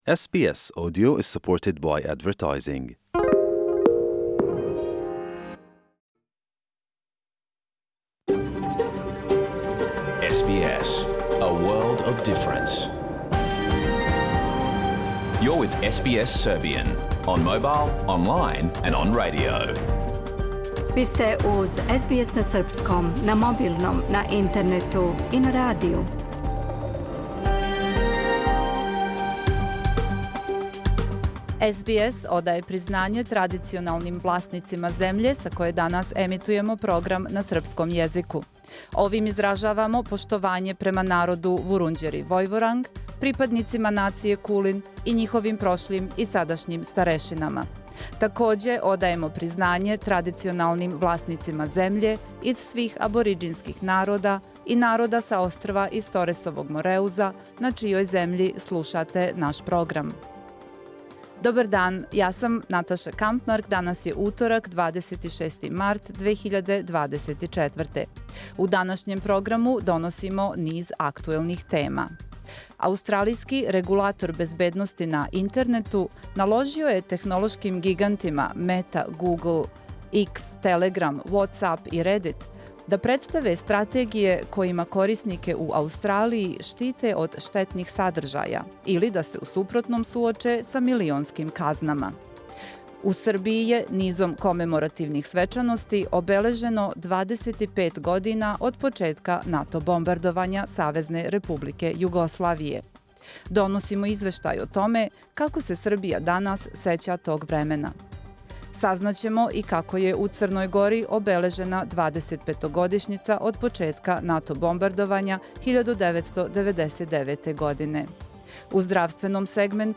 Програм емитован уживо 26. марта 2024. године
Уколико сте пропустили данашњу емисију, можете је послушати у целини као подкаст, без реклама.